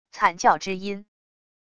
惨叫之音wav音频